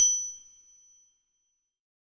POTRIANGLE.wav